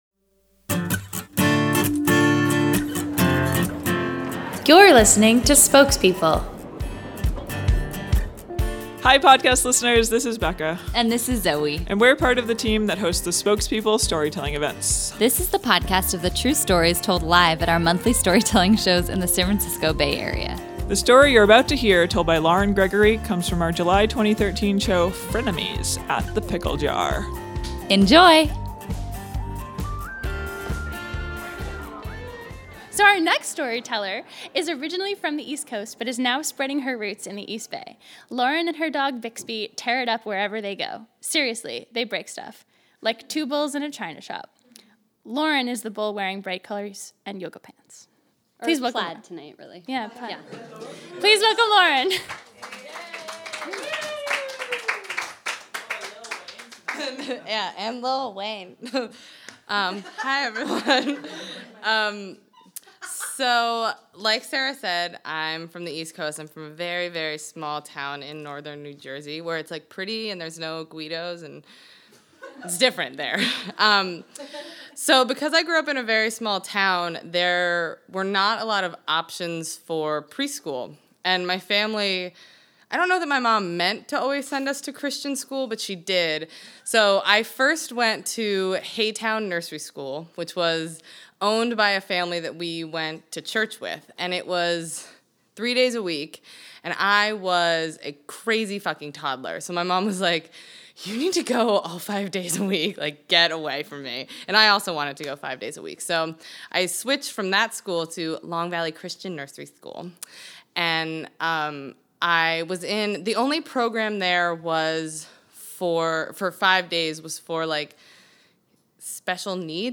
Homepage / Podcast / Storytelling
This story of friendship that starts with a bang comes from our July 2013 show, “Frenemies.”